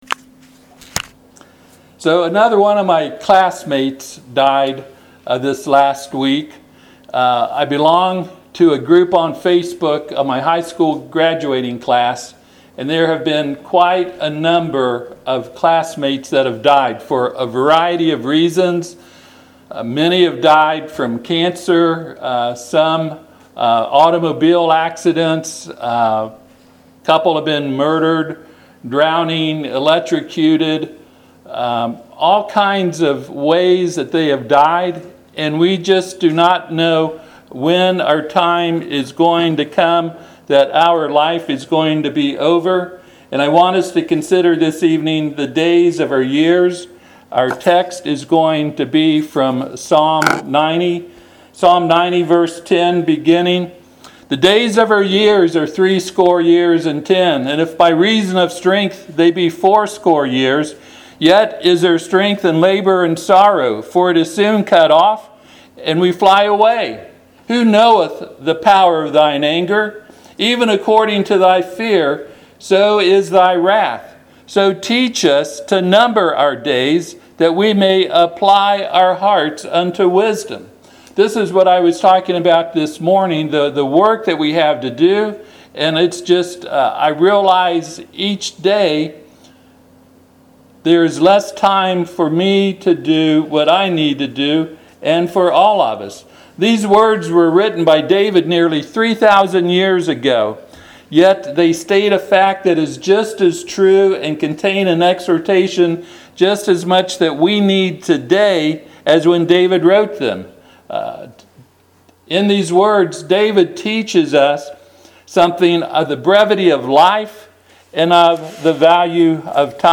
Passage: Psalm 90:10-12 Service Type: Sunday PM